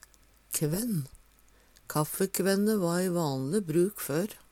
kvenn - Numedalsmål (en-US)